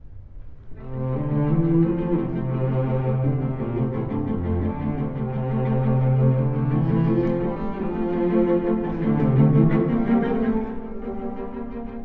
↑古い録音のため聴きづらいかもしれません！（以下同様）
第7番は、ラズモフスキー三部作のなかで最も長く、雄大です。
冒頭のチェロから始まるていねいな上昇音階の時点で、すでにこの曲の雄大さをイメージできます。